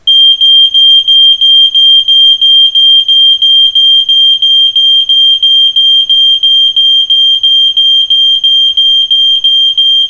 Aus diesem Grund haben wir die Warnmelder Alarme zahlreicher aktueller Modelle für Sie aufgezeichnet.
detectomat-hdv-sensys-rauchmelder-alarm.mp3